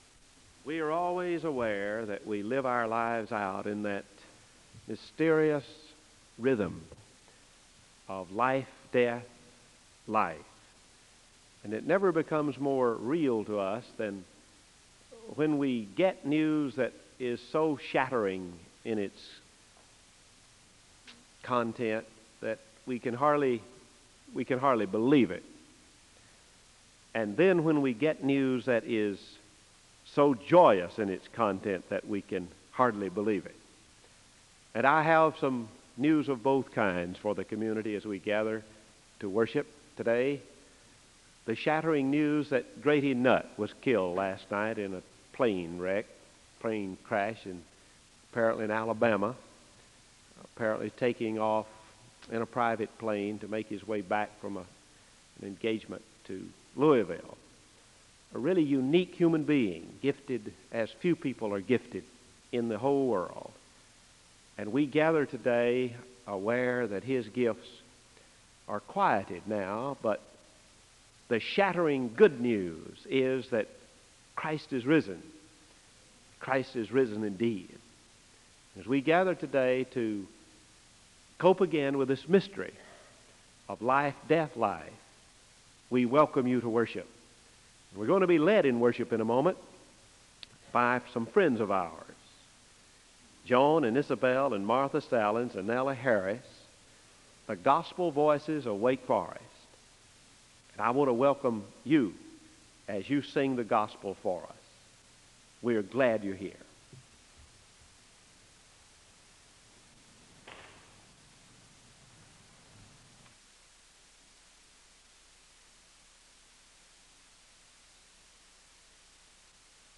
The Gospel Voices of Wake Forest sing a song of worship (01:49-08:27). The speaker delivers a gospel reading from Matthew 16:1-4, and he gives a word of prayer (08:28-13:35).
Gospel singers
Location Wake Forest (N.C.)